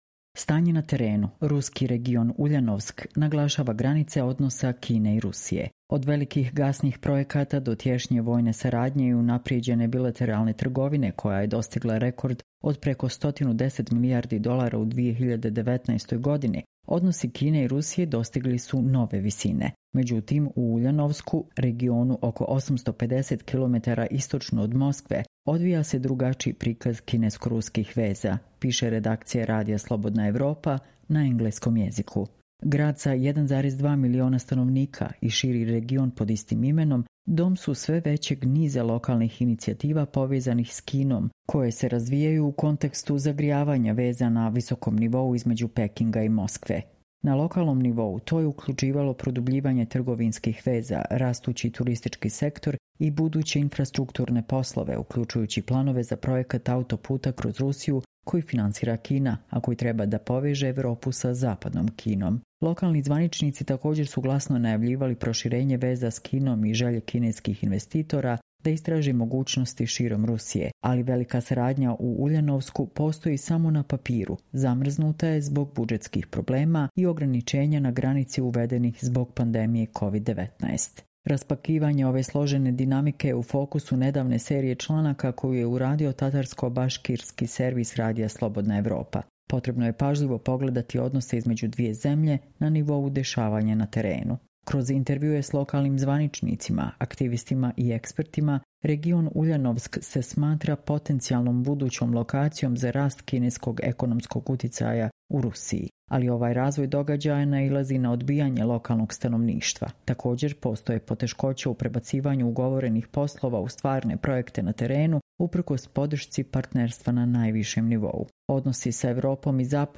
Čitamo vam: Ruski region Uljanovsk naglašava granice odnosa Kine i Rusije